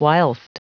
Prononciation du mot whilst en anglais (fichier audio)
whilst.wav